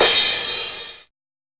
CRASH01   -L.wav